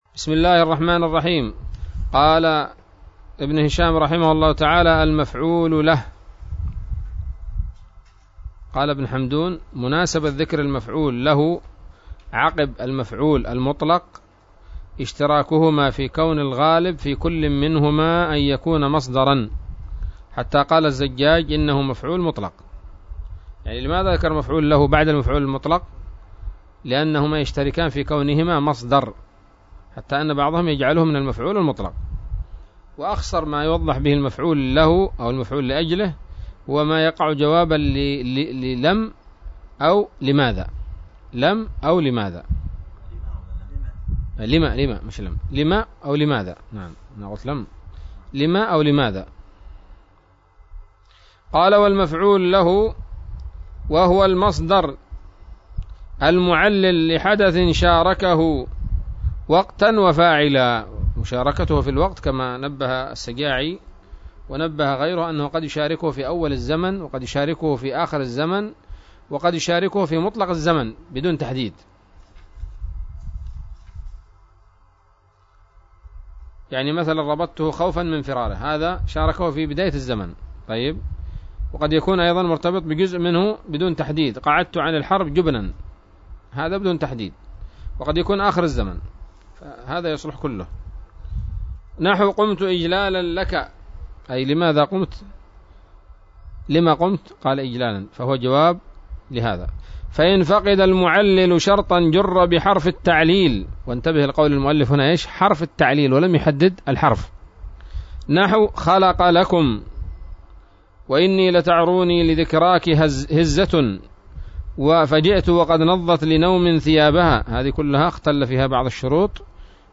الدرس الرابع والتسعون من شرح قطر الندى وبل الصدى